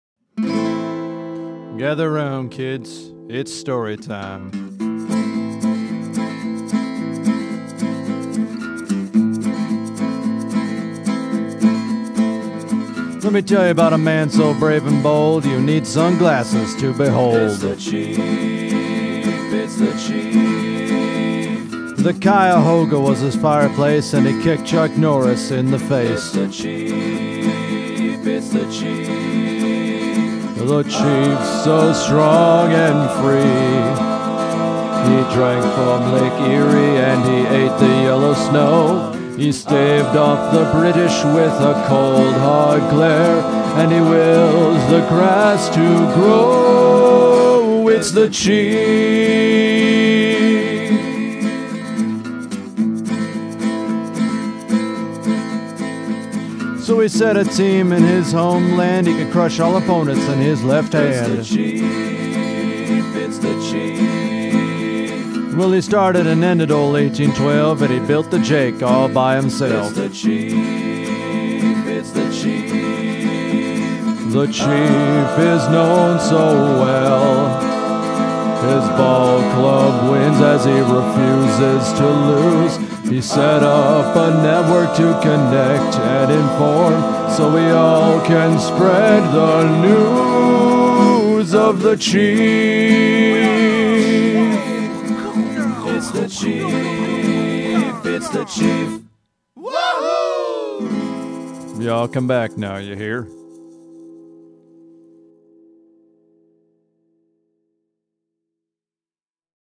Because Cleveland is in that sorta folk-country-rawhide style, I decided to reduce the mix to a more “campfire” style – acoustic guitars, shaker, and vocals.